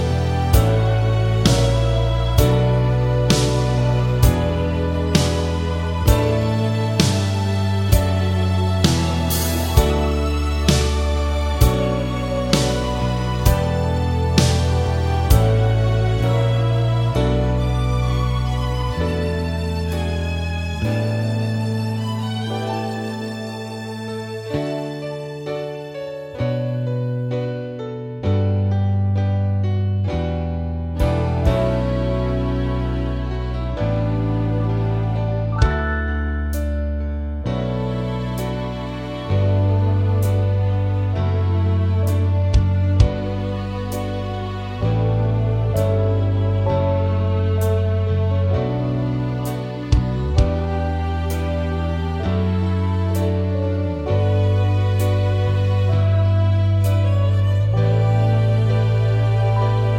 no Backing Vocals Soul / Motown 3:40 Buy £1.50